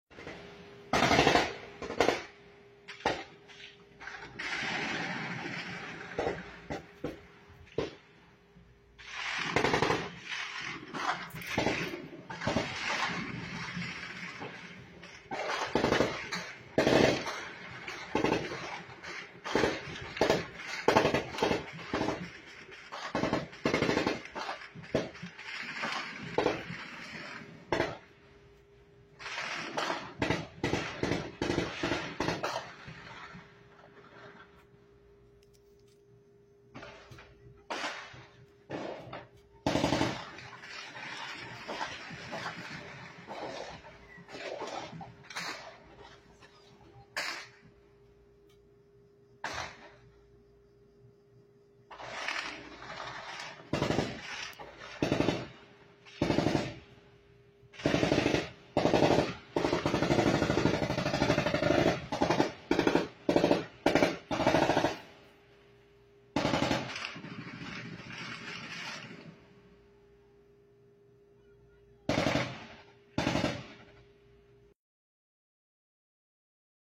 ASI SE ESCUCHO EL ENFRENTAMIENTO DESDE EL INTERIOR DE UNA CASA EN TAMAULIPAS MÉXICO